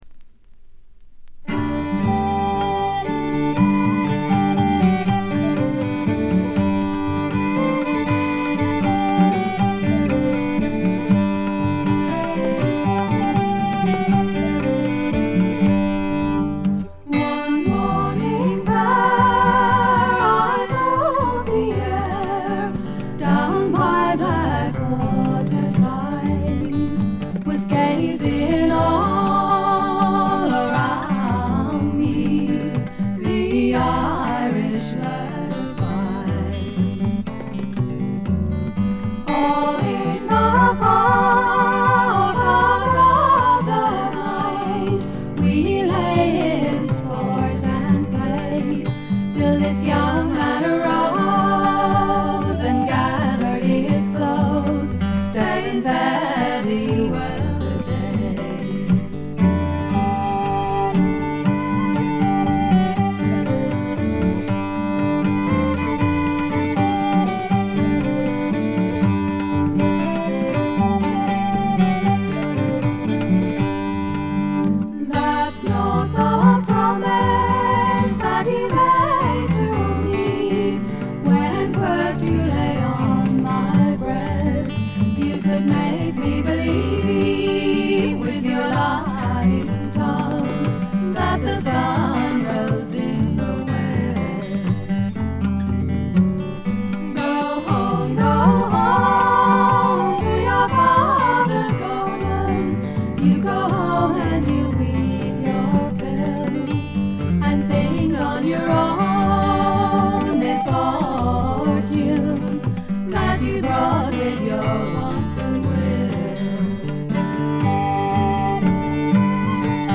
部分試聴ですがほとんどノイズ感無し。
また専任のタブラ奏者がいるのも驚きで、全編に鳴り響くタブラの音色が得も言われぬ独特の味わいを醸しだしています。
試聴曲は現品からの取り込み音源です。
vocals, flute, recorders, oboe, piccolo
fiddle, vocals
tabla, finger cymbals